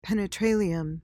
PRONUNCIATION:
(peh-nuh-TRAY-lee-uhm)